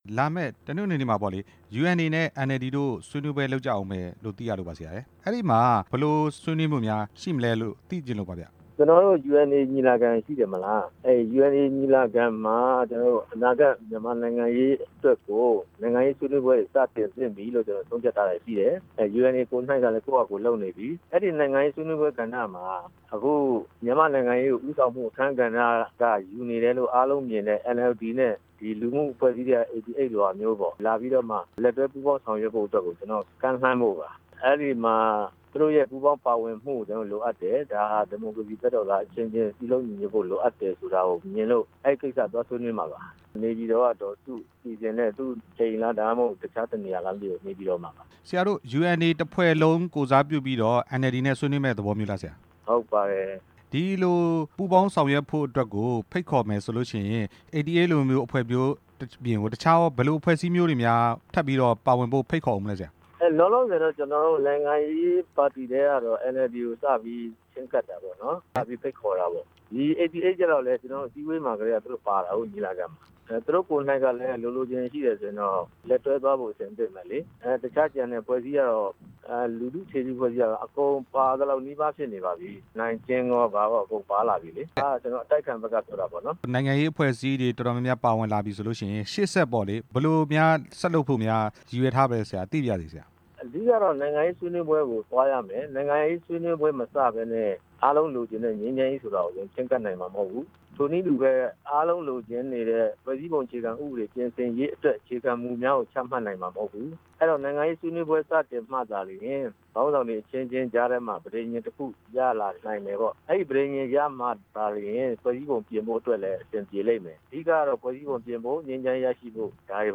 UNA နဲ့ ဒေါ်အောင်ဆန်းစုကြည် ဆွေးနွေးမယ့်အကြောင်း မေးမြန်းချက်